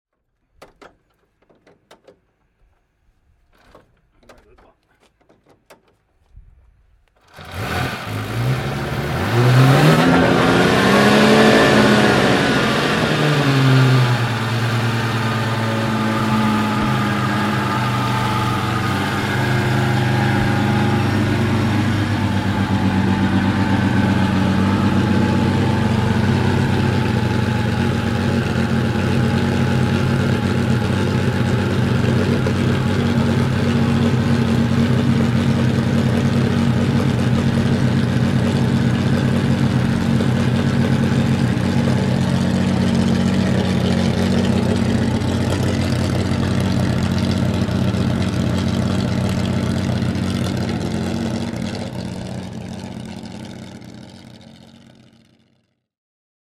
Amilcar C6 (1927) - Starten per Handkurbel und Leerlauf
Amilcar_c6_1927.mp3